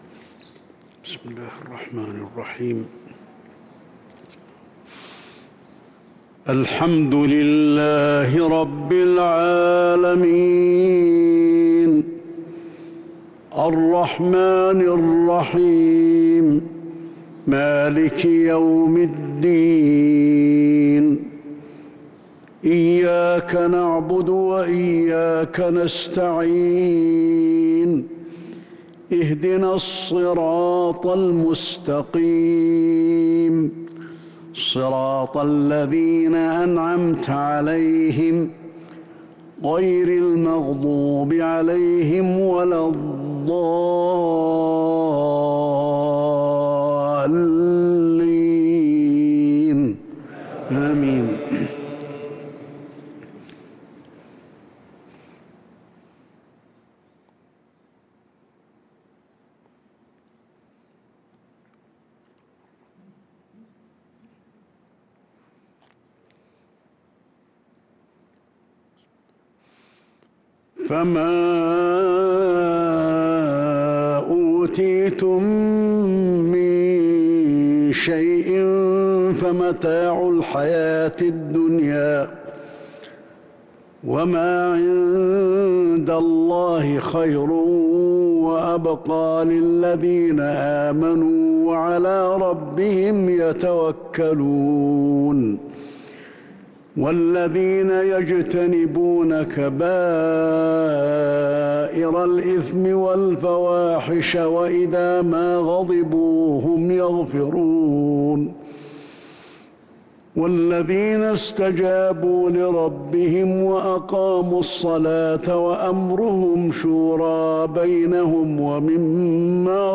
صلاة المغرب للقارئ علي الحذيفي 27 شعبان 1443 هـ
تِلَاوَات الْحَرَمَيْن .